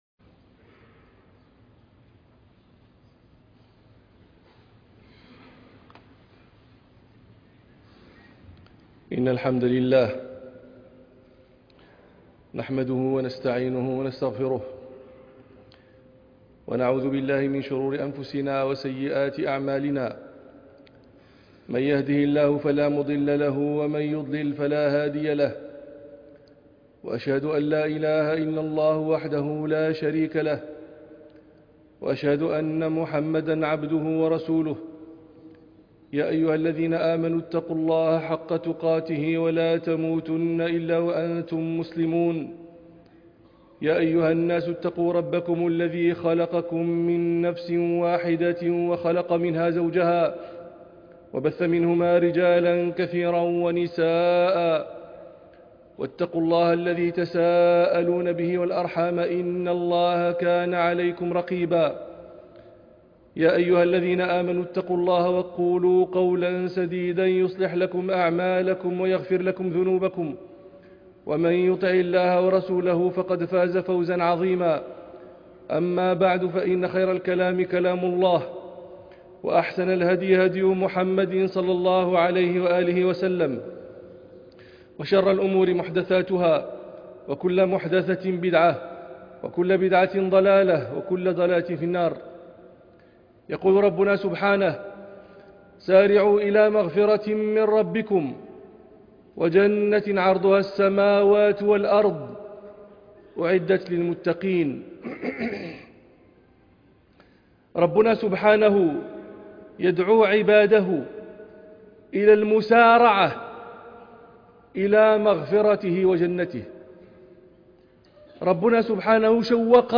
خطبة جمعة مهمة جدّا في البحرين ـ 24 فبراير 2023- تربية وتزكية النفس